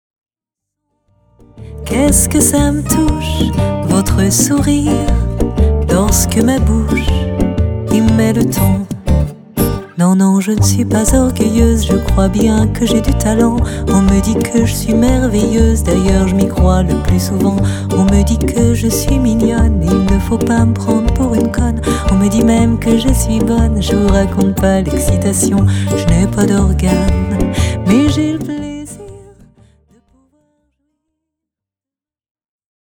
Une voix qui enchante...